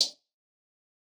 HHAT - THREAT.wav